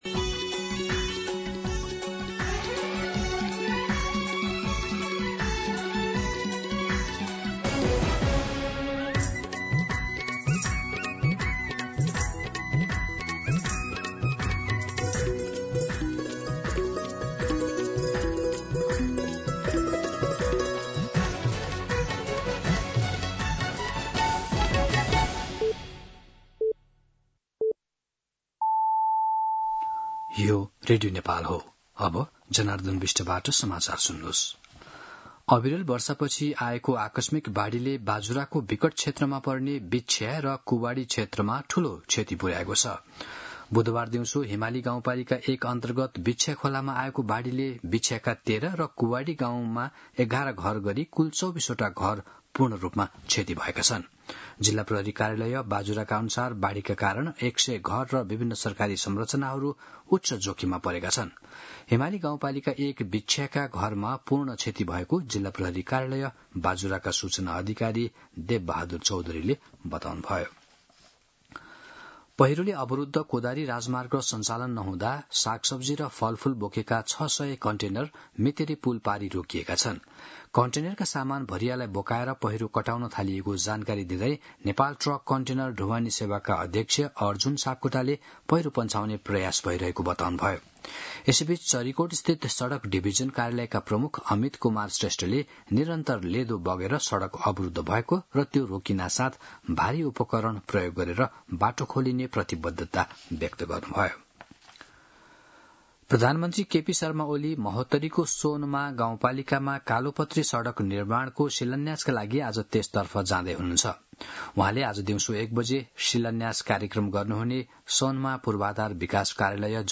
मध्यान्ह १२ बजेको नेपाली समाचार : ३० साउन , २०८२
12-pm-Nepali-News-5.mp3